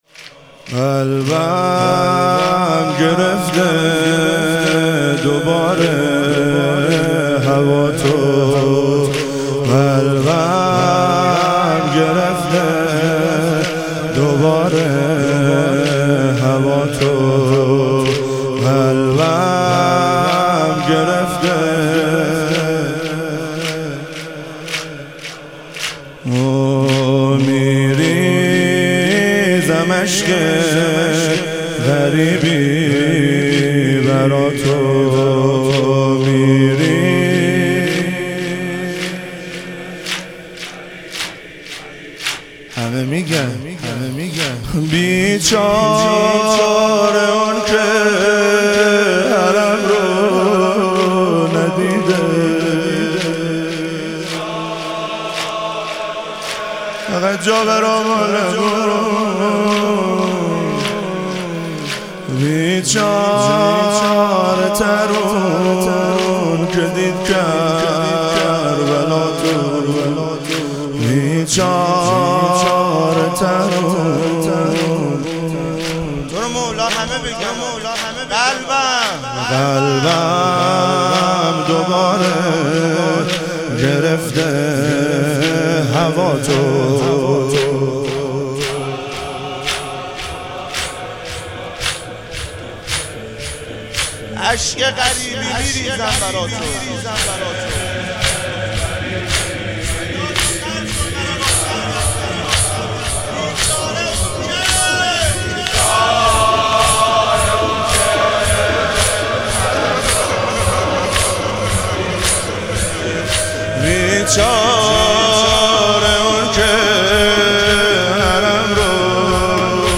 اربعین امام حسین علیه السلام - تک - 2 - 1400